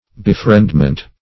Befriendment \Be*friend"ment\, n.
befriendment.mp3